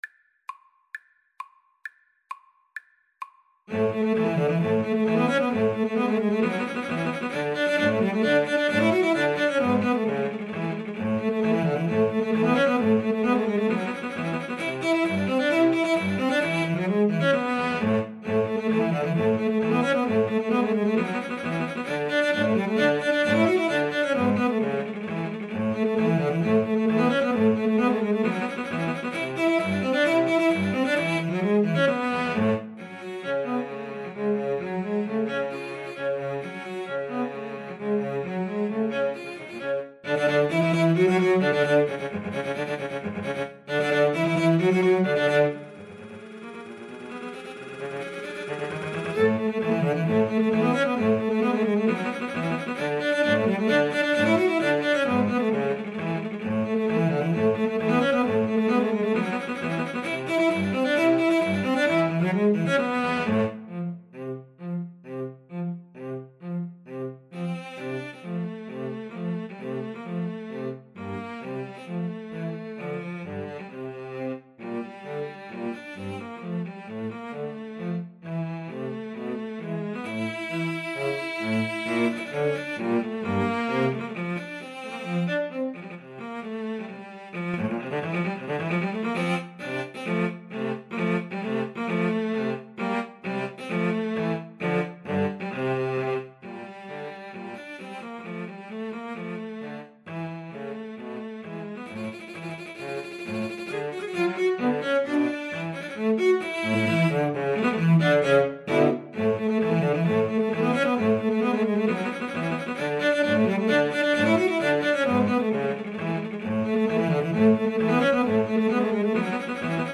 Cello Trio  (View more Intermediate Cello Trio Music)
Classical (View more Classical Cello Trio Music)